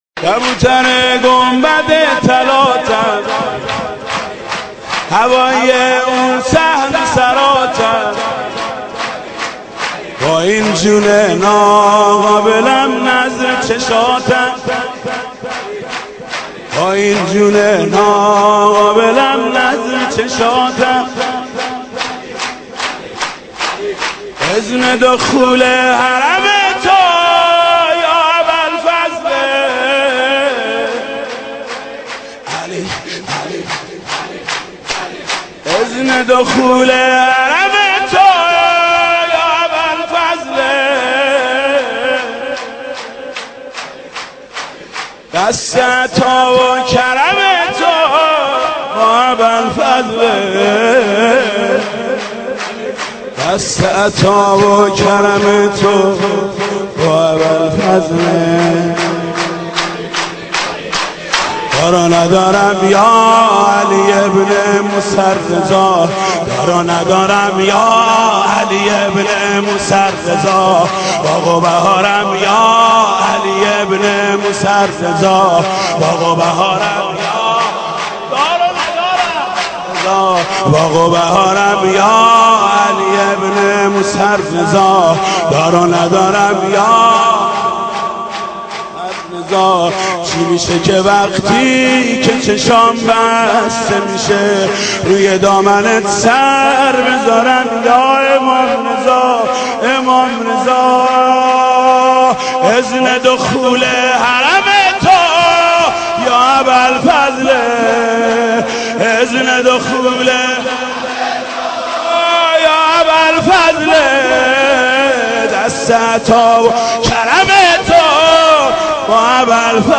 سرود: کبوتر گنبد طلاتم